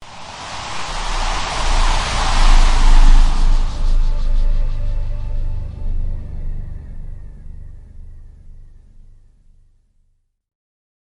Slow Wind Spell is a free nature sound effect available for download in MP3 format.
Slow Wind Spell.mp3